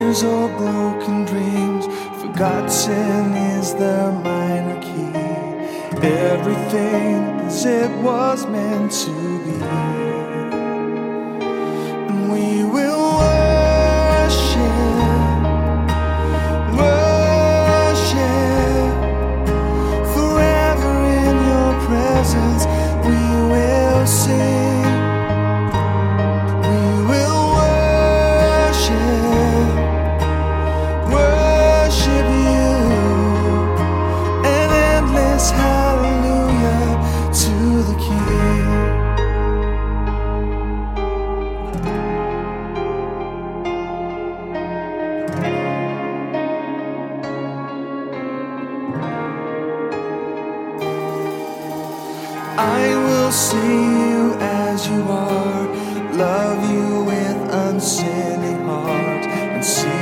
neuen Anbetungslieder
• Sachgebiet: Praise & Worship